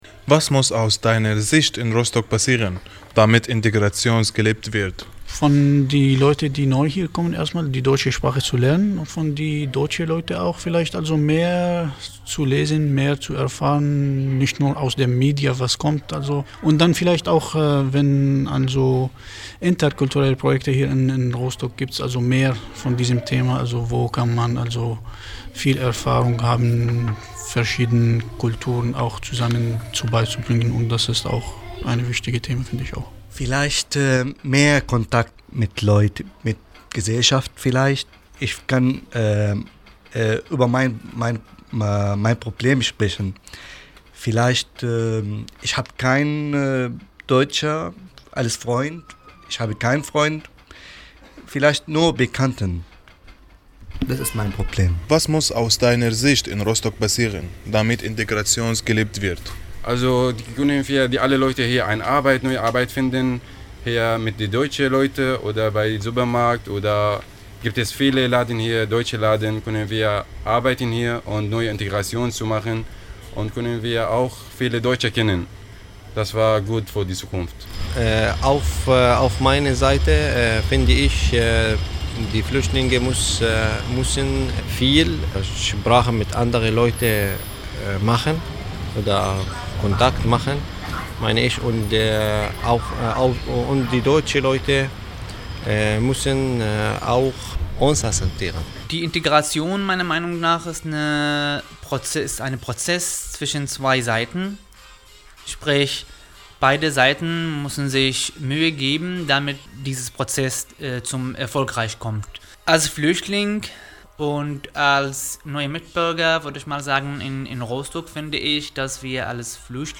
Nachgefragt unter Flüchtlingen: